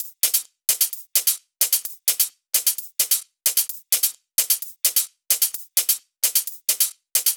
VDE 130BPM Change Drums 7.wav